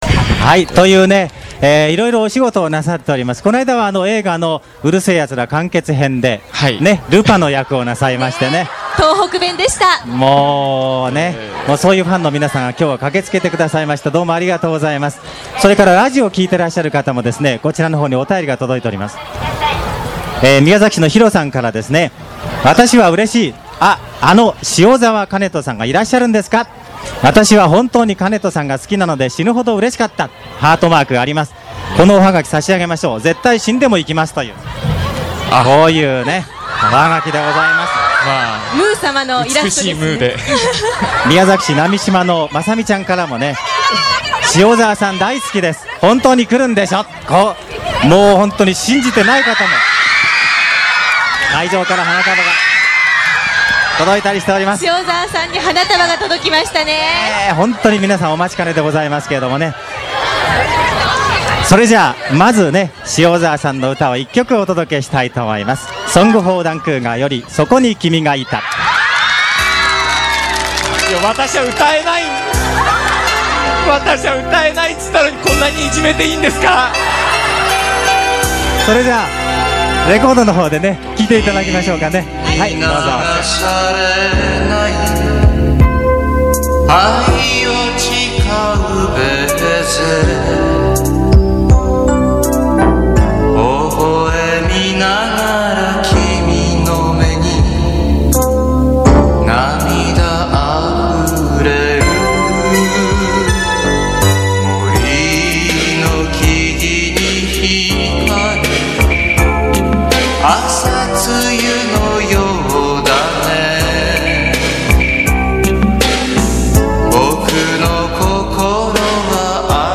「ステージでの歌は勘弁してください。」と言われたのでＬＰを用意していた。